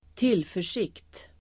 Ladda ner uttalet
Uttal: [²t'il:för_sik:t]